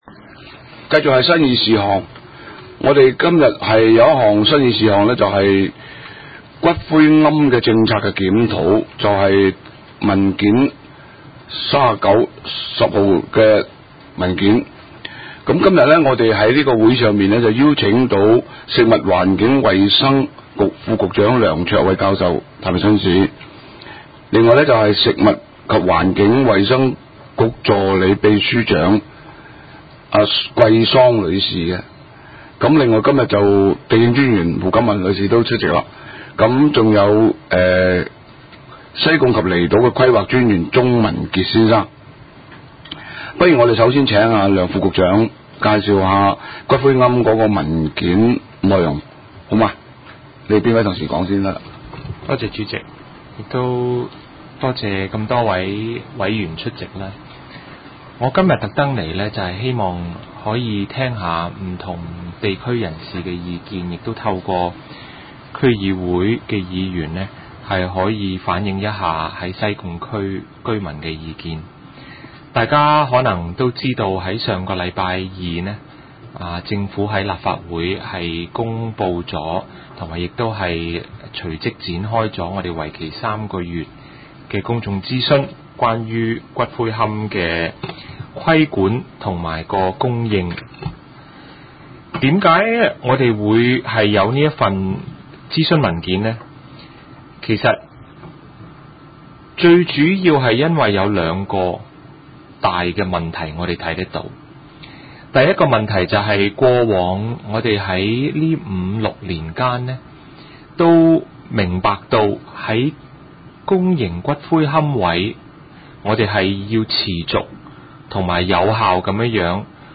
房屋及環境衞生委員會會議